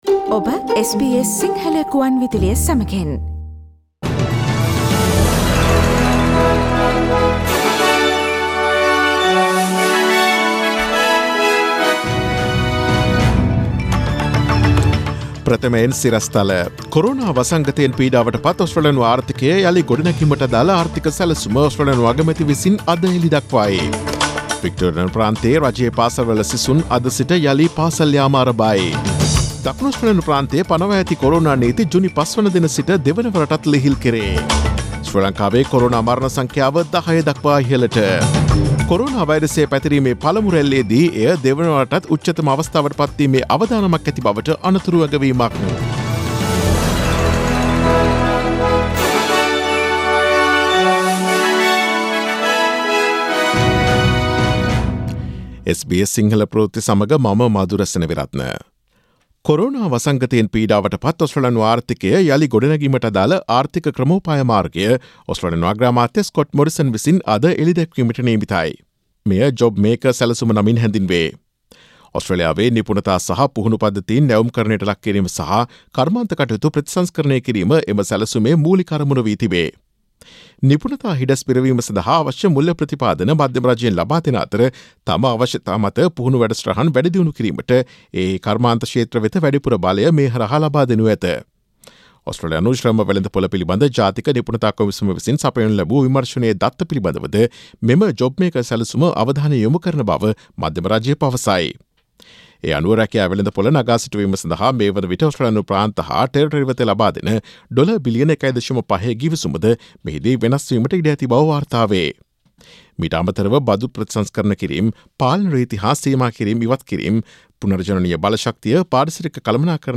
Daily News bulletin of SBS Sinhala Service: Tuesday 26 May 2020